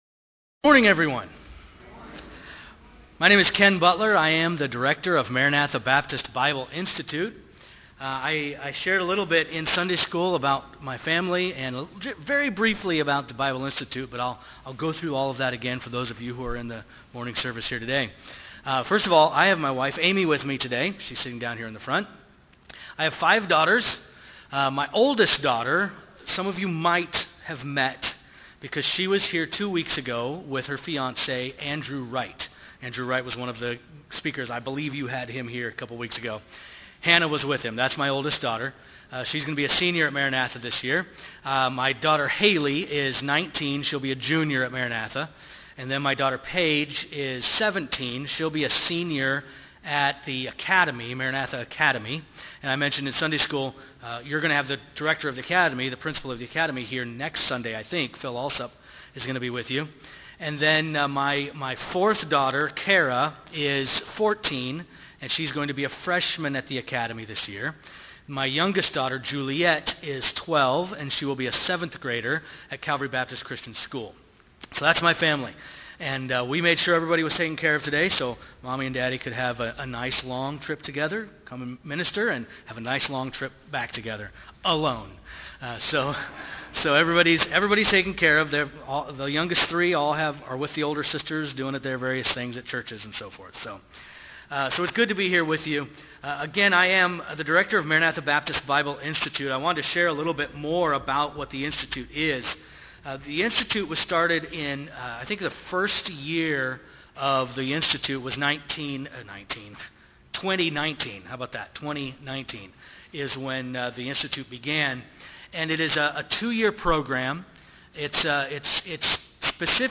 Sermons :: First Baptist of Rochelle